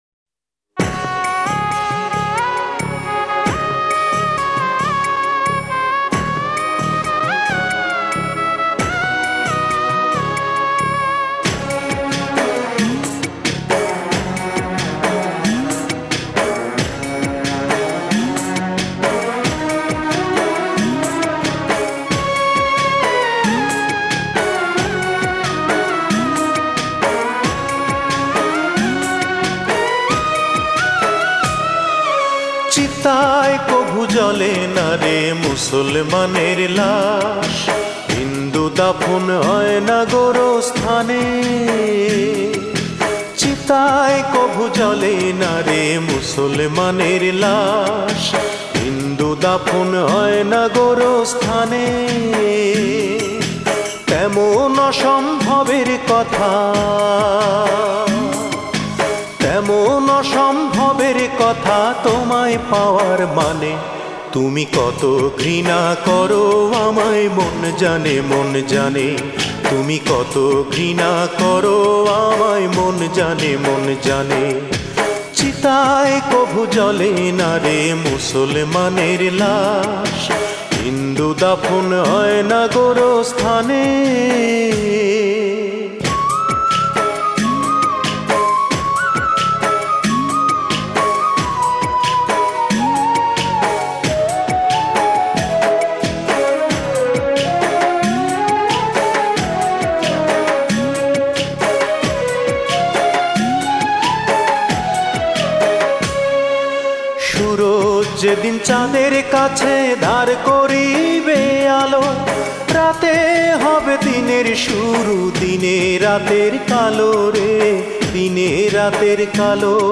Genre Adhunik Bangla